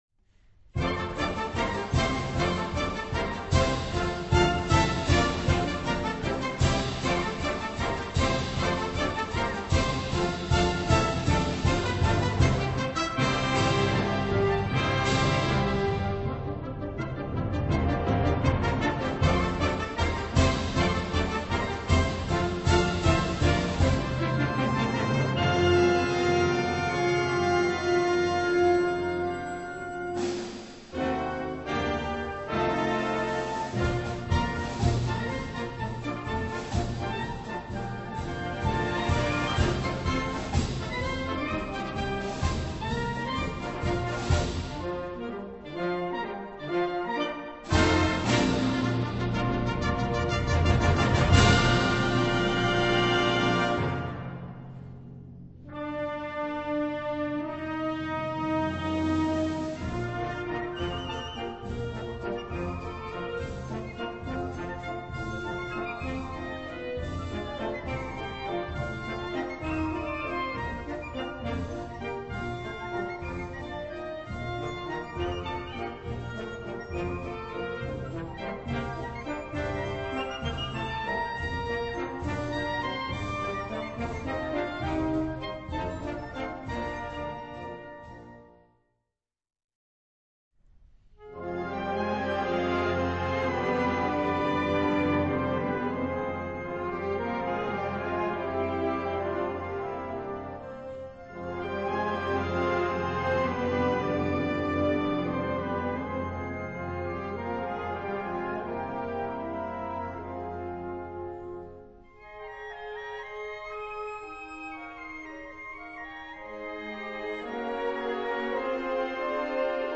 Gattung: Operette
Besetzung: Blasorchester